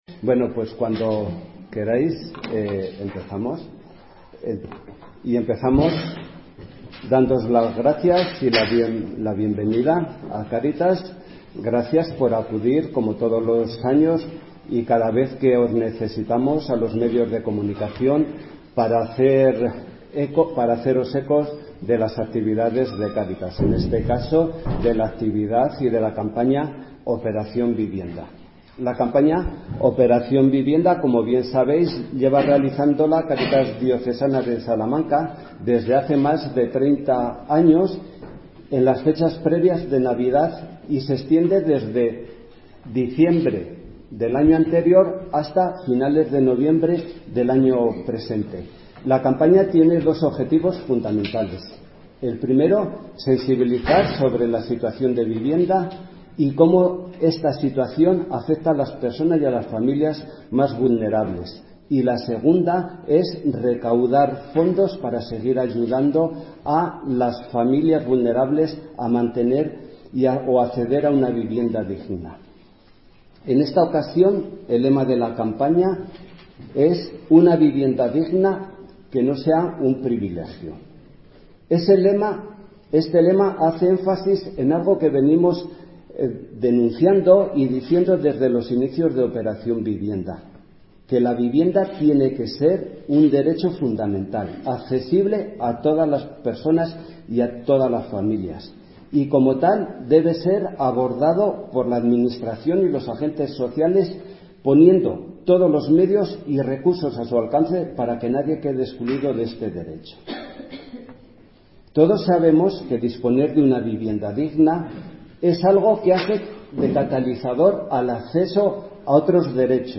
Rueda-de-prensa-Operacion-Vivienda-2022.mp3